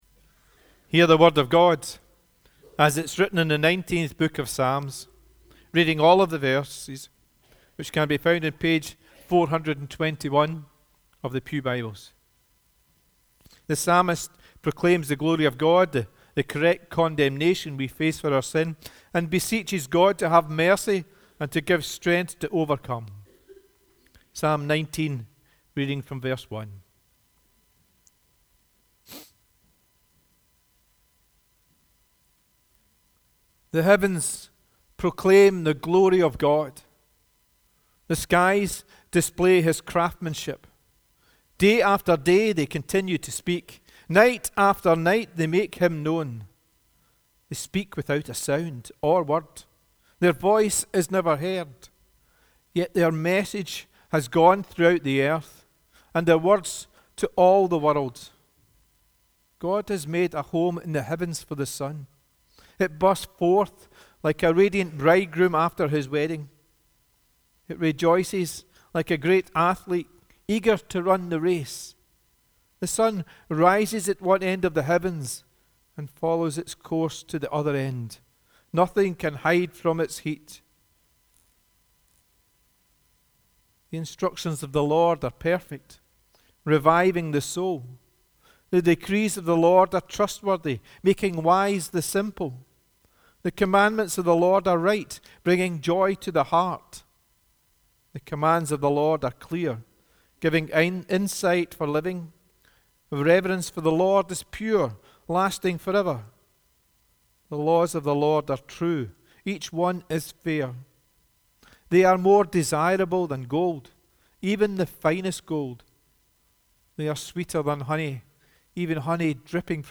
The Bible Reading prior to the sermon is Psalm 19